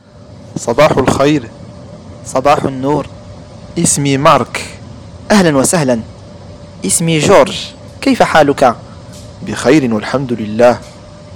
AVION-DIALOGUE-1.mp3